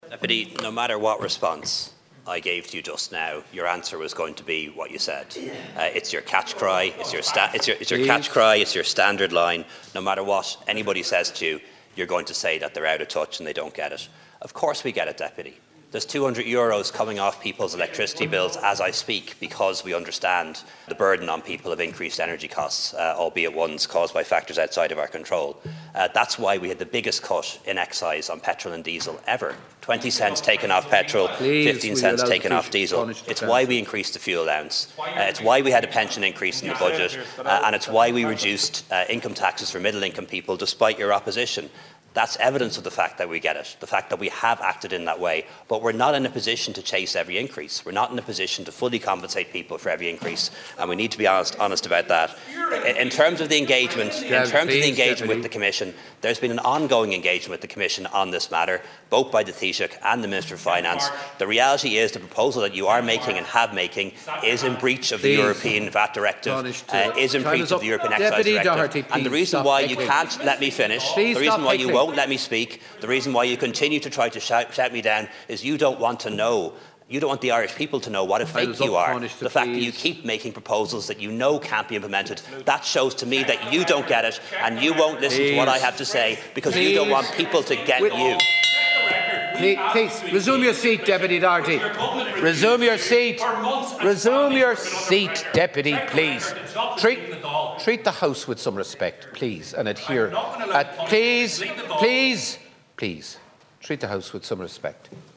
The Ceann Comhairle has reprimanded Donegal Deputy Pearse Doherty in the Dail following heated exchanges with the Tanaiste.